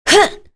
Tanya-Vox_Attack1.wav